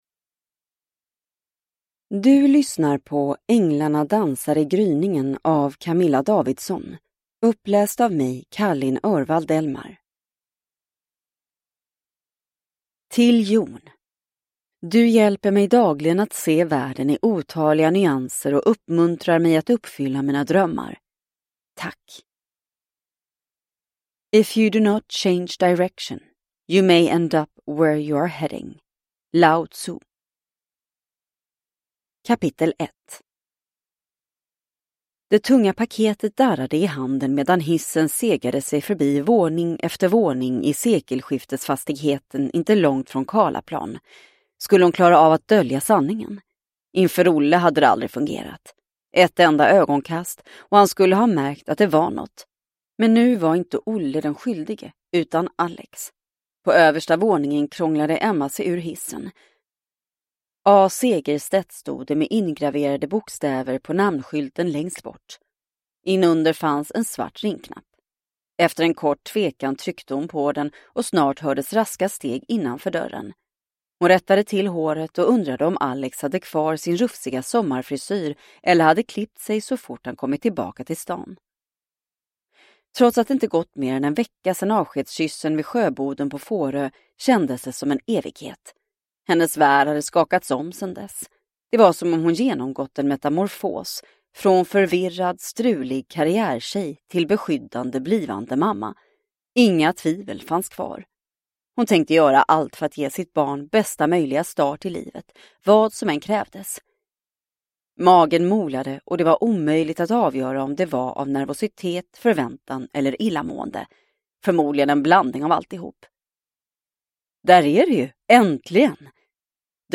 Änglarna dansar i gryningen – Ljudbok – Laddas ner